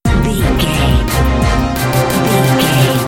Fast paced
Aeolian/Minor
B♭
strings
drums
horns